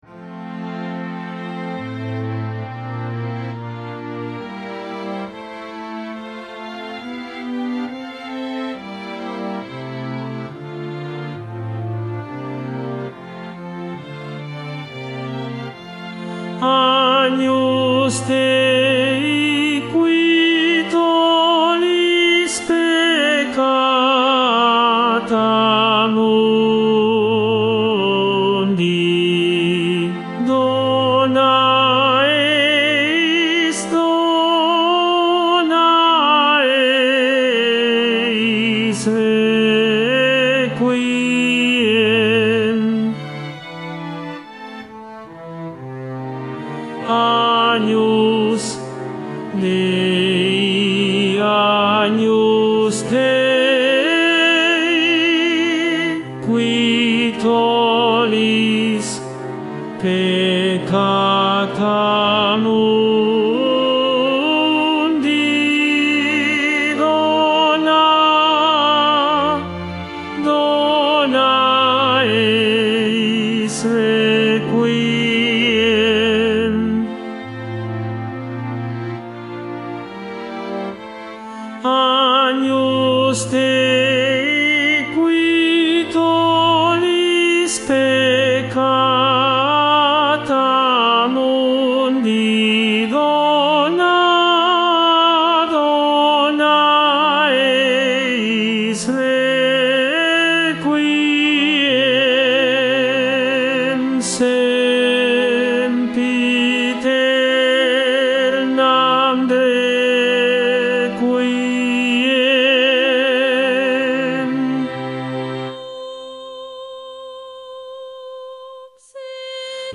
Tenor I